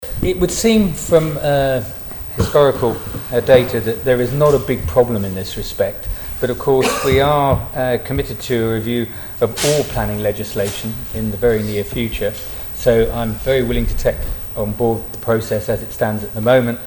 If the planning application has already been determined any aggrieved person would have to bring a legal challenge to the decision - Minister Geoffrey Boot says it's not a common problem: